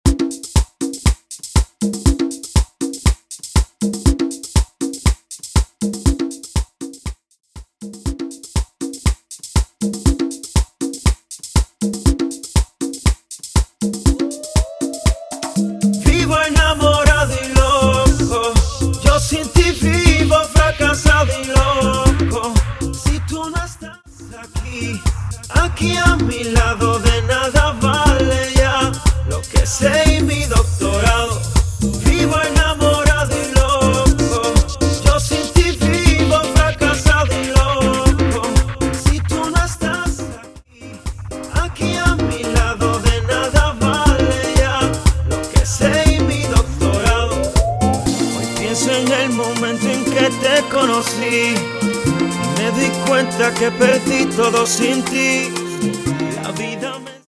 Specializing in Latin genres